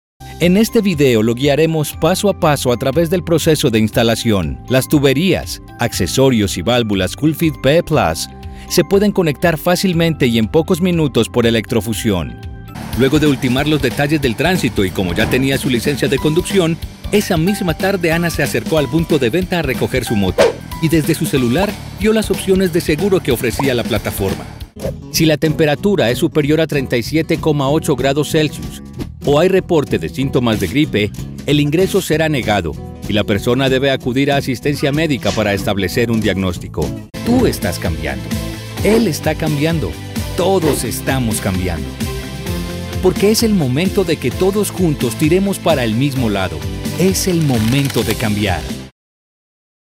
A young, fresh and versatile voice
Educational and E-Learning
Colombian, Latinamerican Neutral Spanish
Young Adult